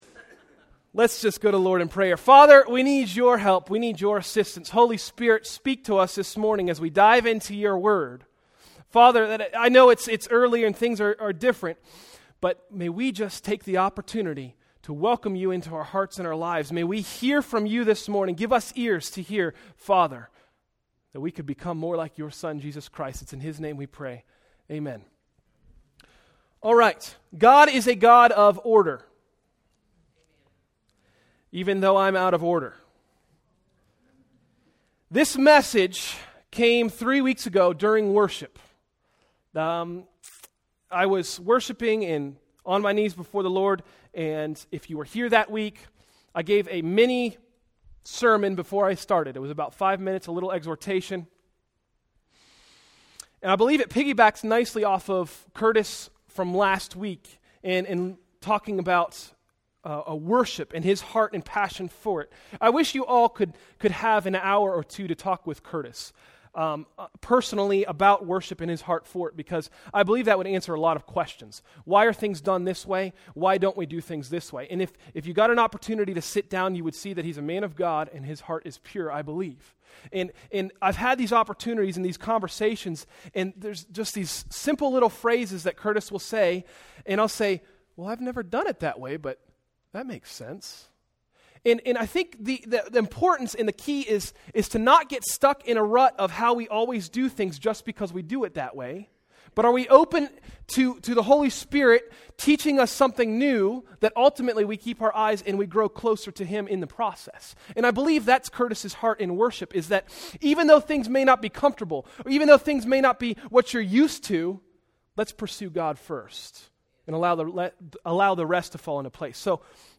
Sermons: “Worship”